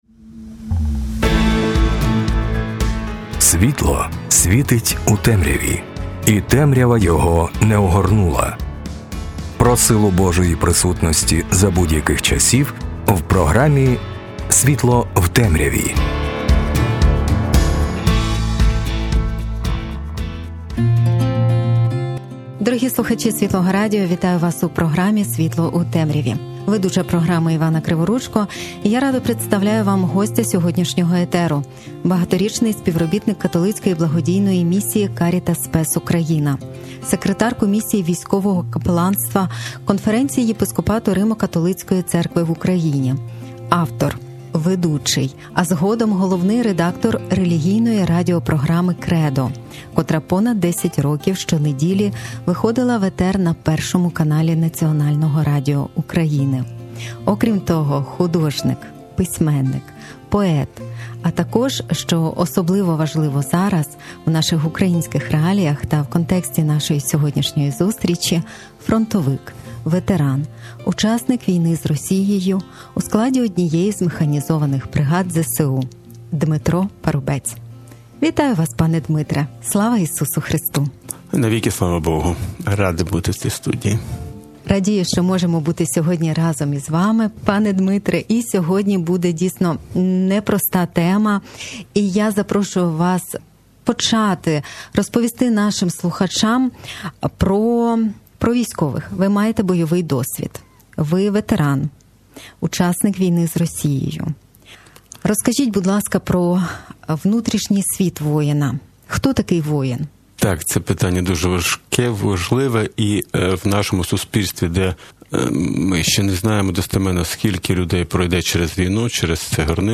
Розмова з воїном про виклики, які чекають на ветерана в цивільному житті, Ч.1 | Архів Світлого Радіо